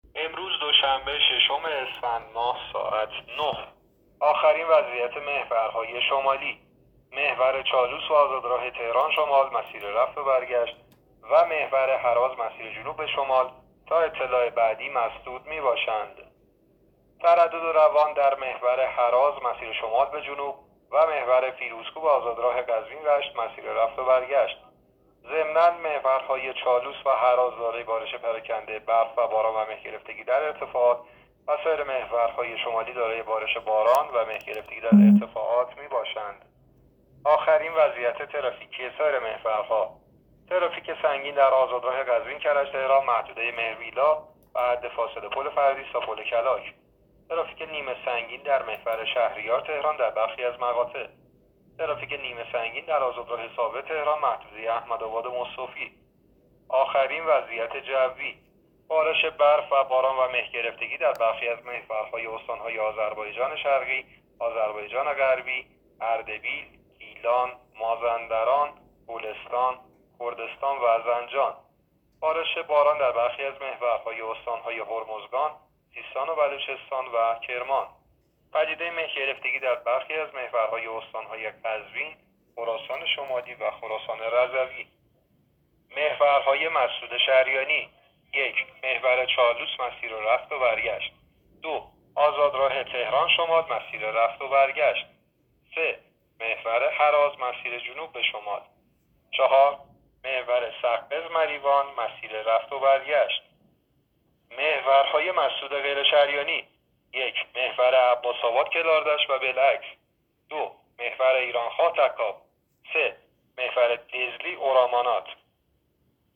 گزارش رادیو اینترنتی از آخرین وضعیت ترافیکی جاده‌ها ساعت ۹ ششم اسفند؛